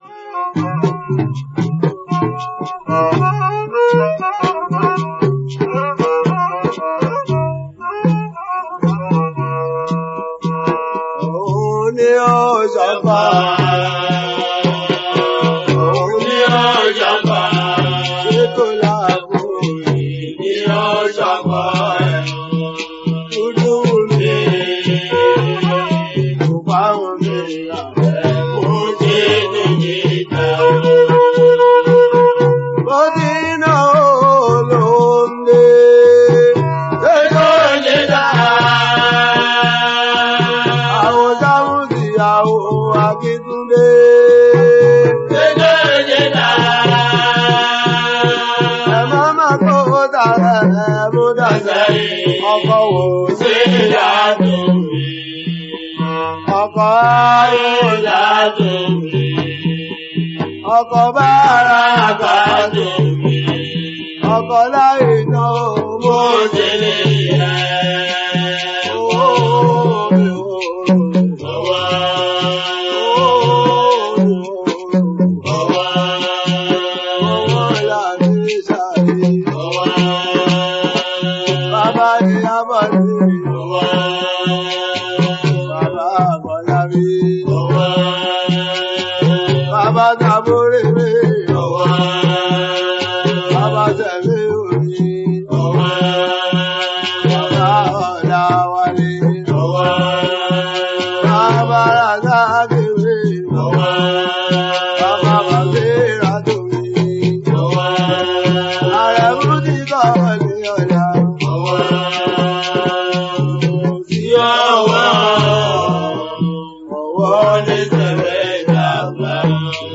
Sakara Music performer and song composer
Yoruba Fuji song
Fuji song